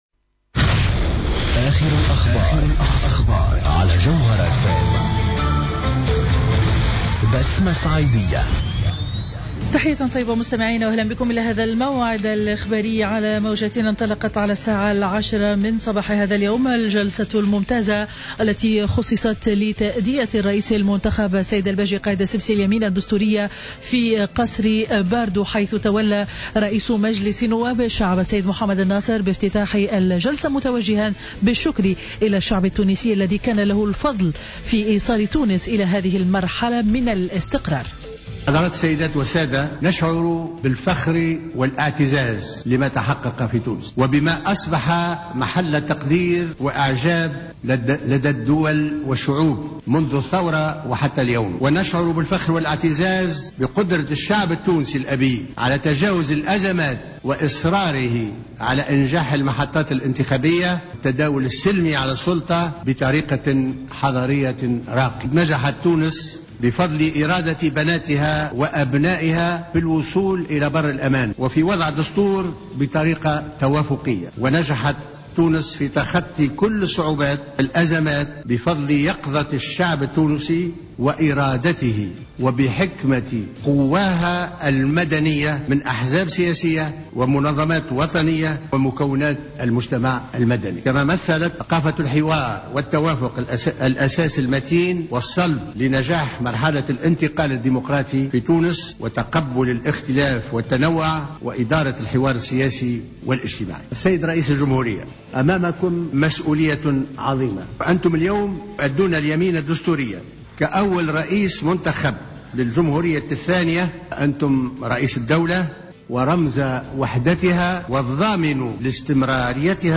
نشرة أخبار منتصف النهار الاربعاء 31-12-14